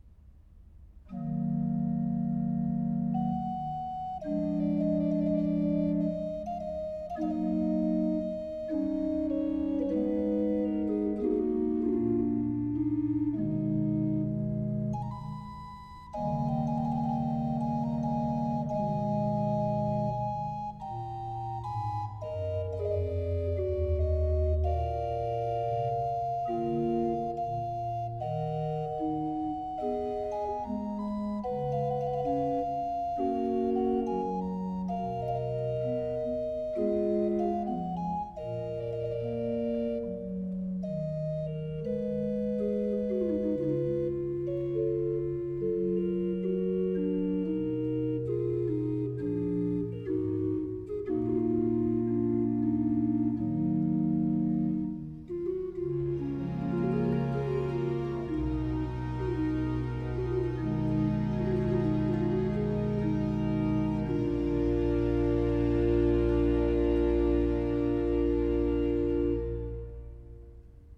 Классическая Музыка
Organ Concerto in G minor